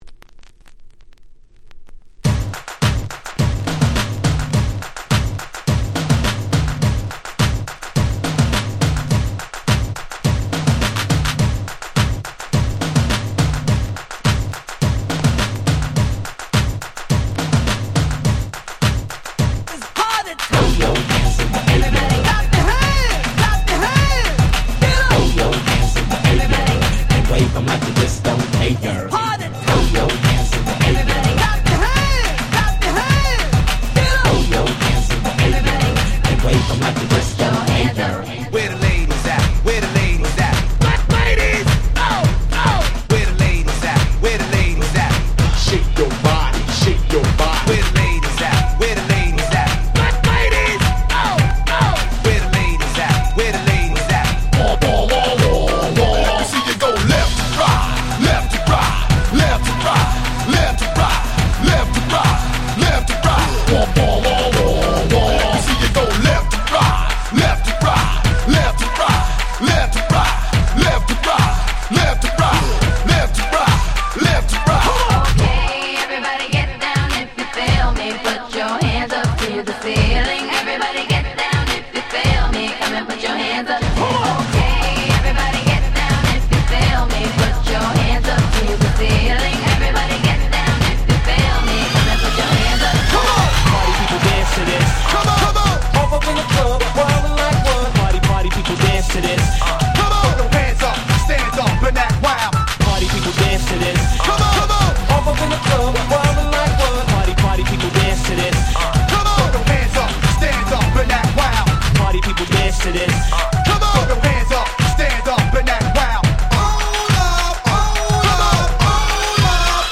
White盤オンリーのアゲアゲParty Tracks/勝手にRemix物！！